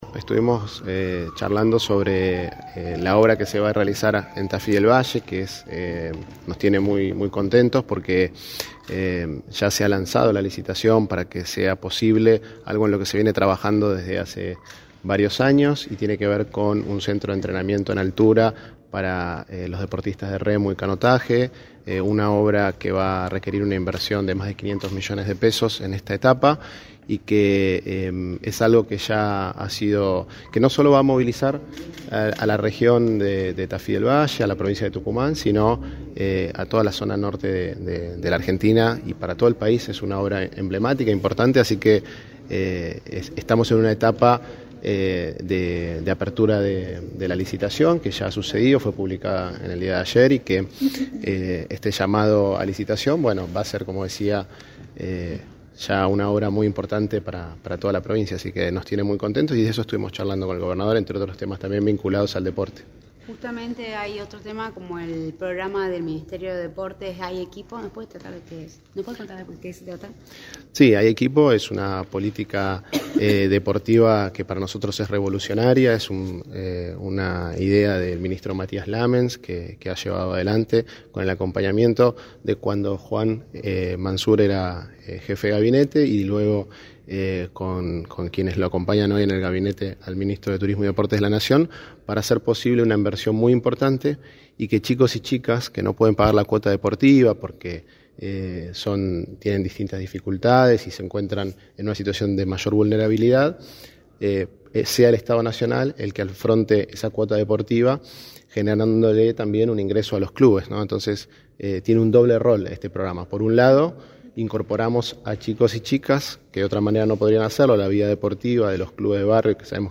Sergio Palma, Subsecretario de Deportes de la Nación, anunció en Radio del Plata Tucumán, por la 93.9, la autorización a la licitación por 2 millones de dólares por el centro de alto rendimiento en altura, en Tafí del Valle para la práctica del canotaje.
“No solo va a movilizar a la región de Tafí del Viaje y Tucumán, sino a todo el norte de Argentina, es una obra emblemática para todo el país, estamos en una etapa de apertura de licitación” señaló Sergio Palma en entrevista para “La Mañana del Plata”, por la 93.9.